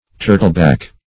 Meaning of turtleback. turtleback synonyms, pronunciation, spelling and more from Free Dictionary.
turtleback.mp3